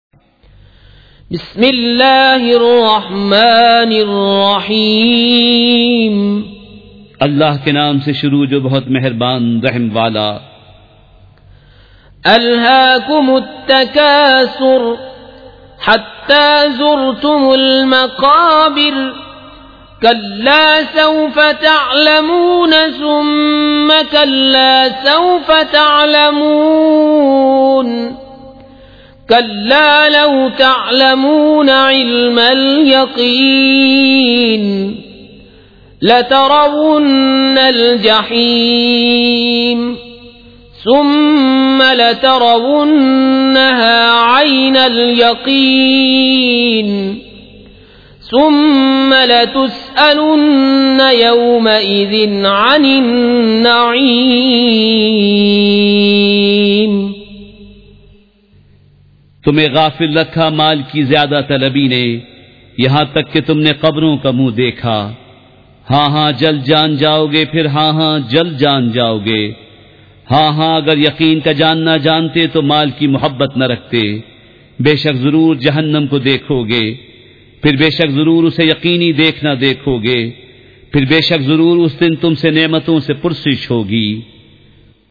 سورۃ التکاثر مع ترجمہ کنزالایمان ZiaeTaiba Audio میڈیا کی معلومات نام سورۃ التکاثر مع ترجمہ کنزالایمان موضوع تلاوت آواز دیگر زبان عربی کل نتائج 1644 قسم آڈیو ڈاؤن لوڈ MP 3 ڈاؤن لوڈ MP 4 متعلقہ تجویزوآراء